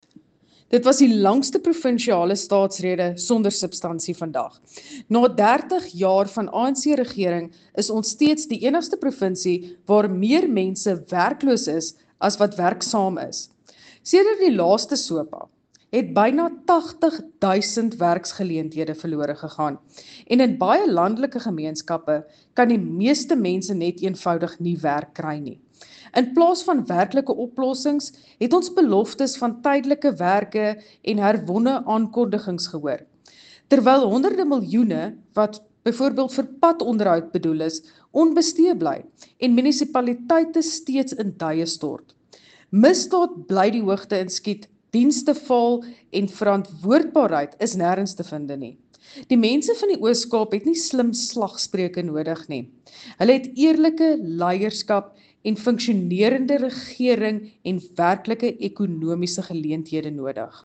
Afrikaans from Dr Vicky Knoetze MPL